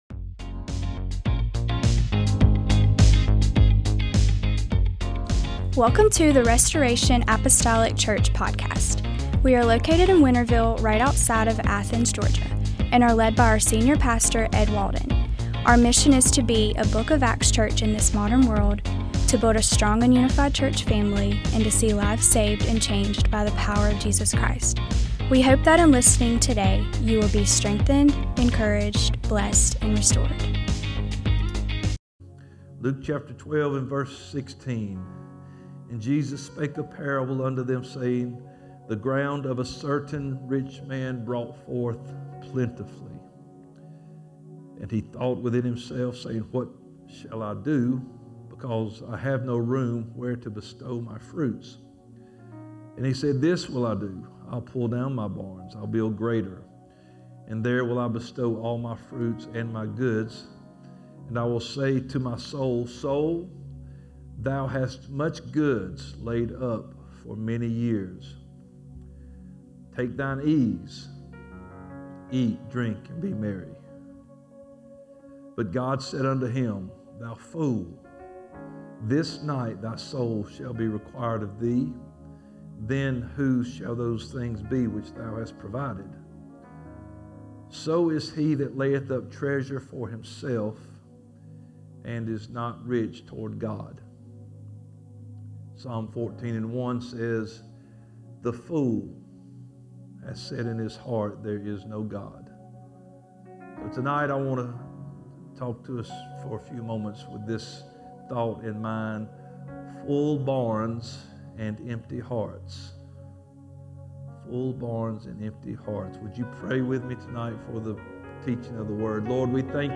MDWK Service